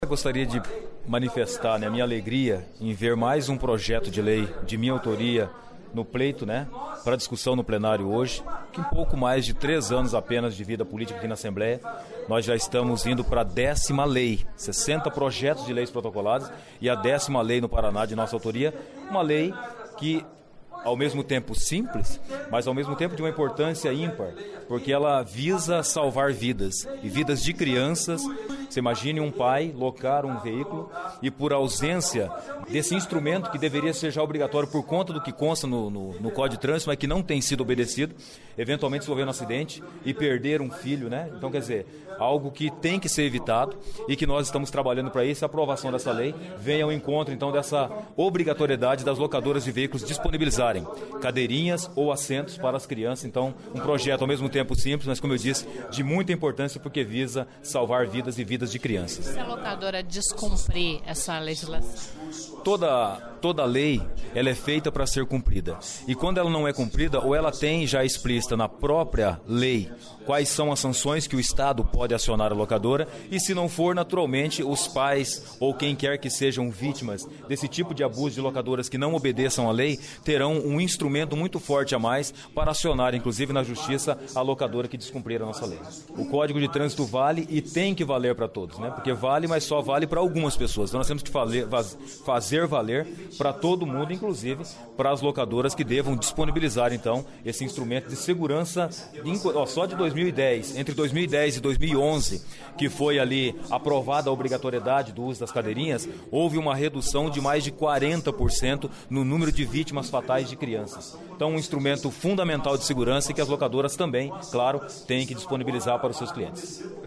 Nós conversamos com a autor da proposta que obrigada Locadoras de veículos  a fornecer cadeirinha para e assento de elevação para  clientes, o deputdo Márcio pacheco (PPL). Se o projeto for sancinado, será a décima lei de autoria do parlamentar em três anos de mandato. vamos ouvir a entrevista.